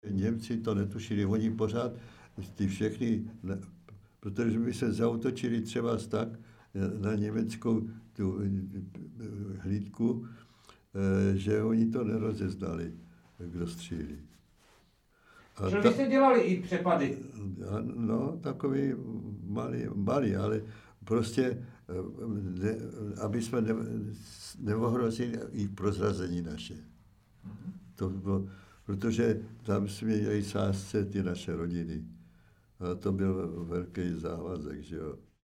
natočený v rámci vyprávění